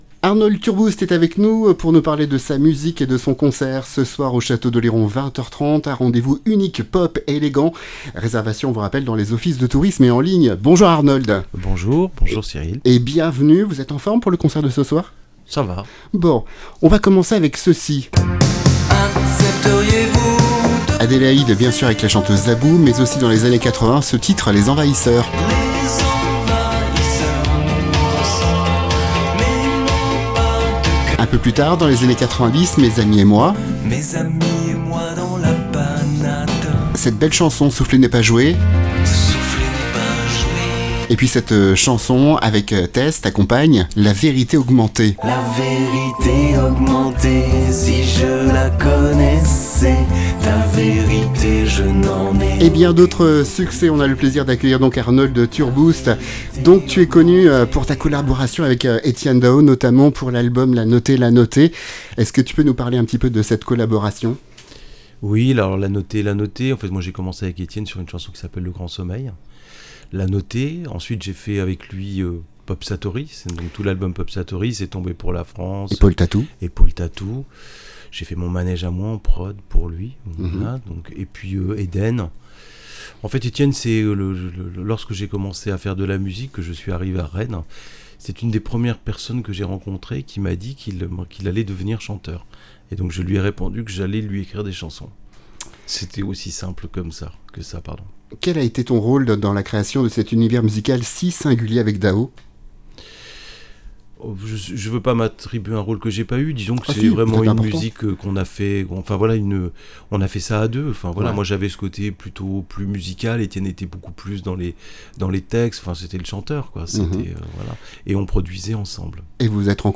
Interwieu d'Arnold Turboust à l'occasion de son concert au Château d'Oléron .